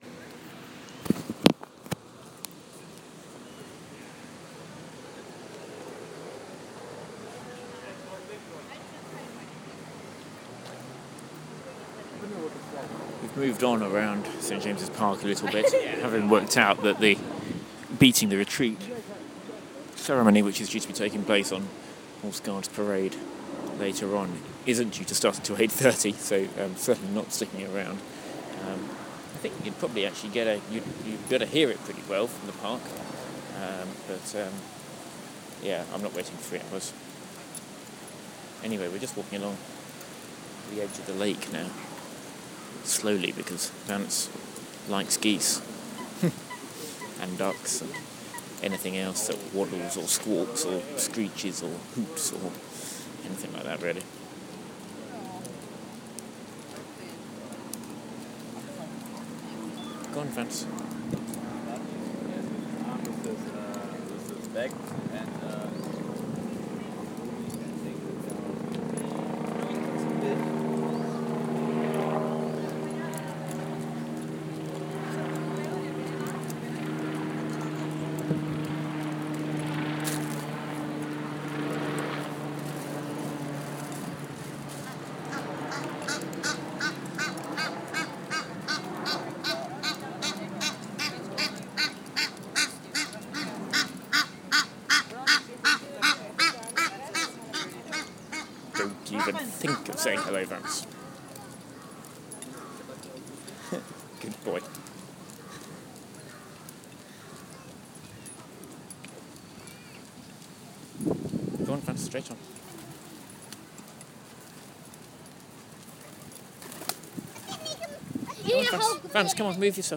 We walk beside the duckpond in St James' Park.